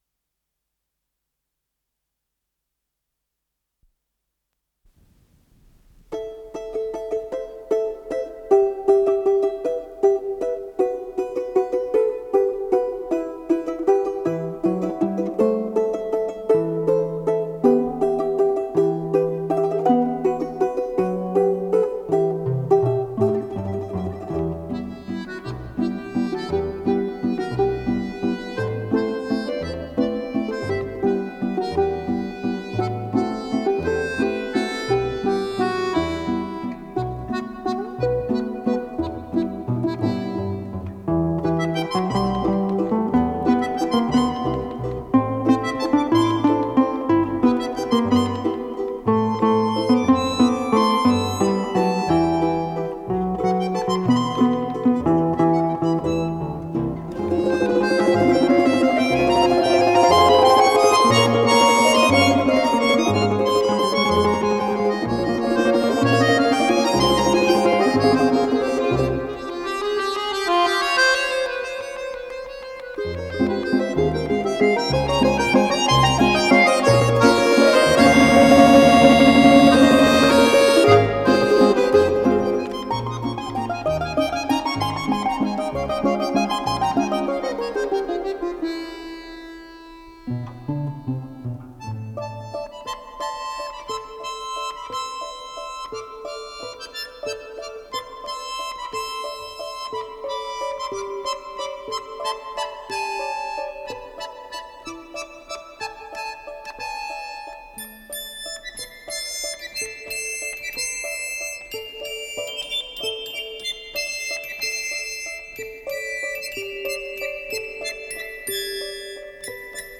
с профессиональной магнитной ленты
ИсполнителиКвартет солистов Государственного Академического русского народного оркестра им. Н. Осипова
домра-альт
баян
балалайка-прима
балалайка-контрабас
ВариантДубль моно